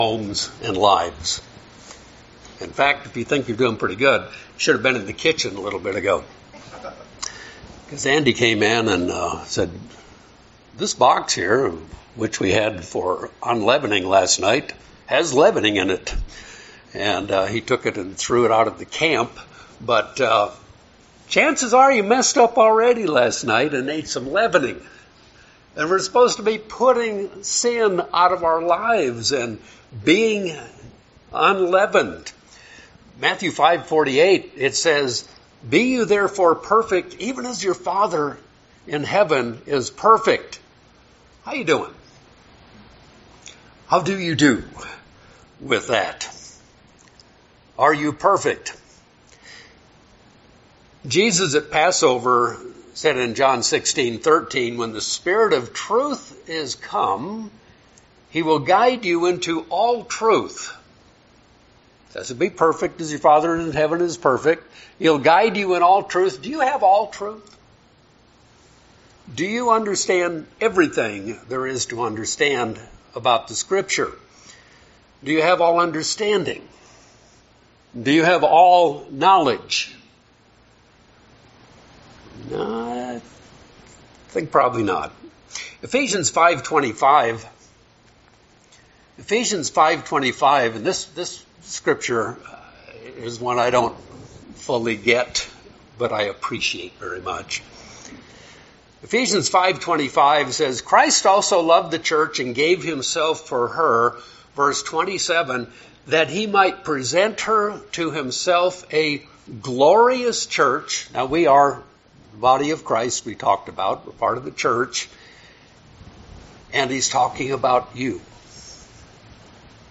While we have been blessed with the knowledge of the truth, how does God view us when we have imperfect actions due to our imperfect knowledge? This sermon explores "Hezekiah's Passover" to help us understand how we can obey God with sincerity and truth.